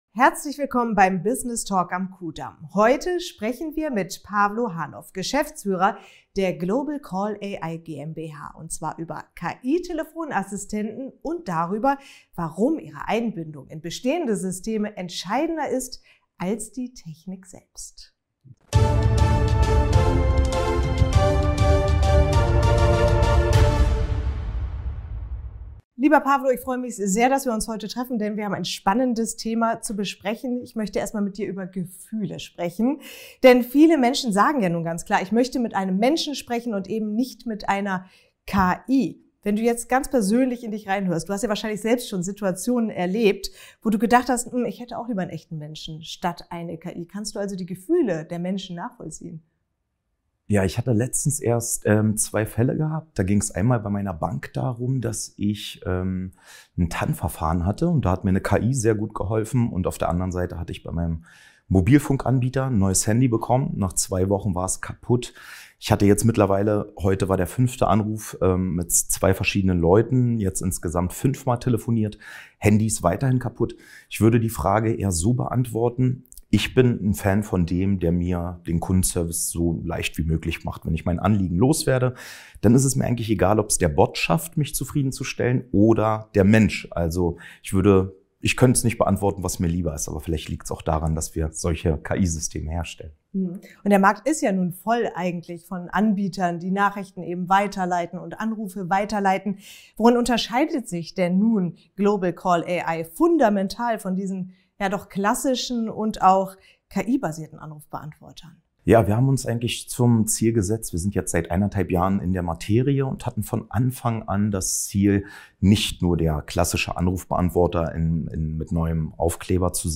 Was können KI-Telefonassistenten heute wirklich – und wo liegen ihre Grenzen? In diesem Interview